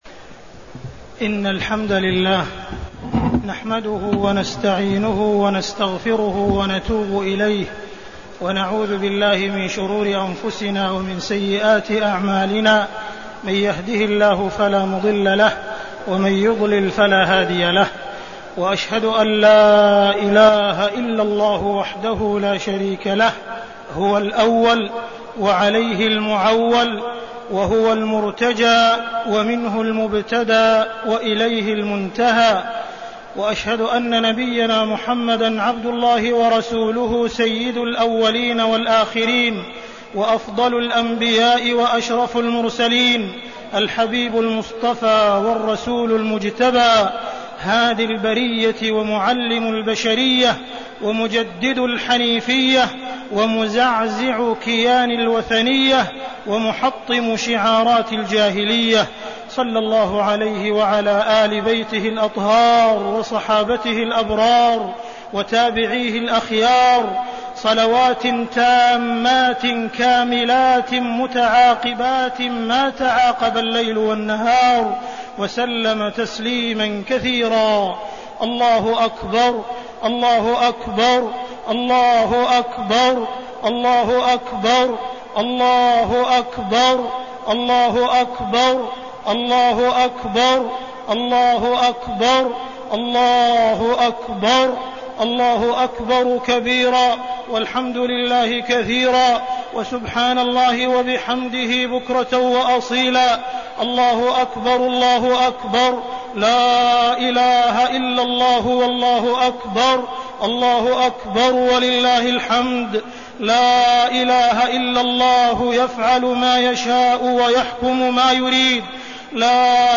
خبطة عيد الأضحى-يوم الحج الأكبر
تاريخ النشر ١٠ ذو الحجة ١٤١٨ هـ المكان: المسجد الحرام الشيخ: معالي الشيخ أ.د. عبدالرحمن بن عبدالعزيز السديس معالي الشيخ أ.د. عبدالرحمن بن عبدالعزيز السديس خبطة عيد الأضحى-يوم الحج الأكبر